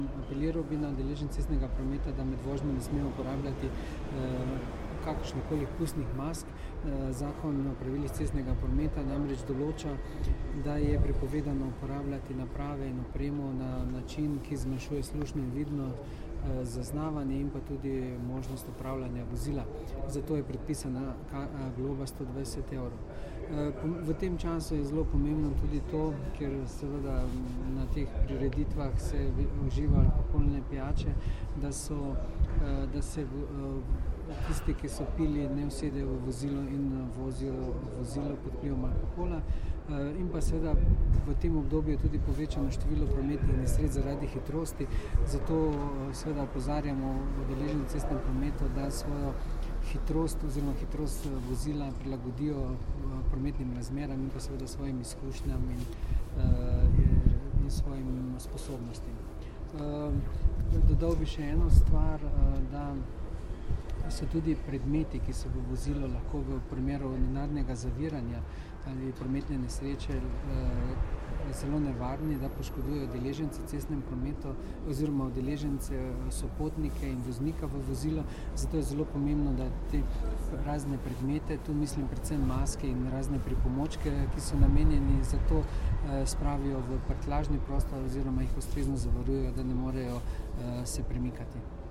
Zvočni posnetek izjave mag.